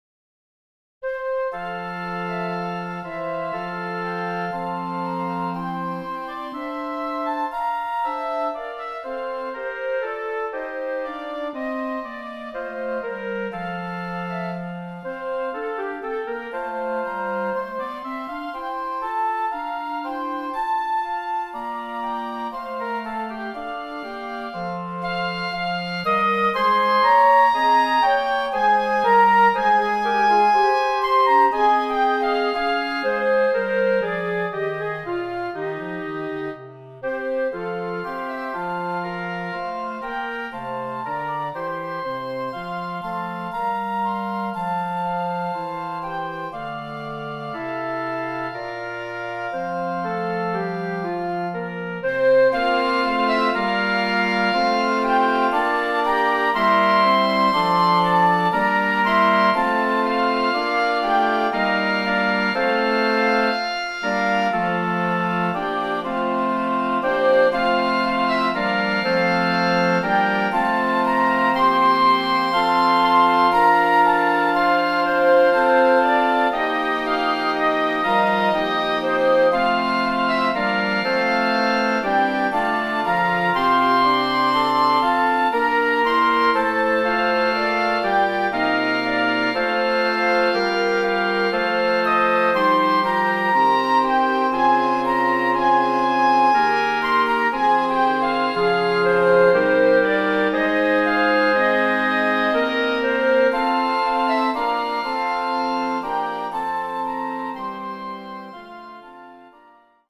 Voicing: Woodwind Ensemble